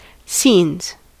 Ääntäminen
Vaihtoehtoiset kirjoitusmuodot (vanhahtava) scænes Ääntäminen US : IPA : [siːnz] Haettu sana löytyi näillä lähdekielillä: englanti Käännöksiä ei löytynyt valitulle kohdekielelle.